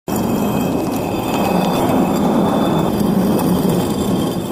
Rock Sound Effects MP3 Download Free - Quick Sounds